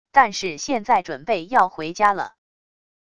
但是现在准备要回家了wav音频生成系统WAV Audio Player